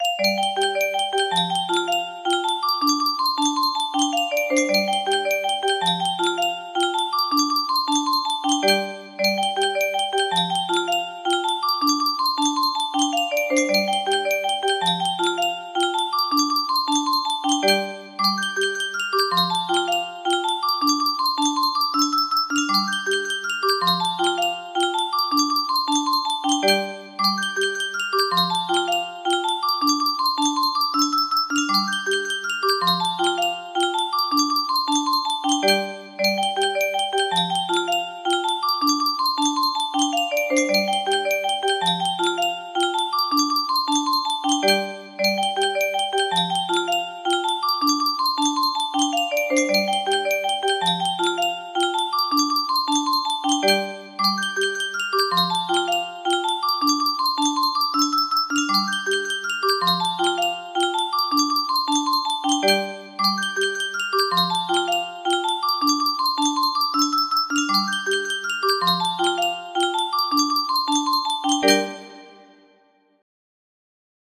The Banshee (Irish jig) music box melody
The Banshee (Irish jig)
Grand Illusions 30 (F scale)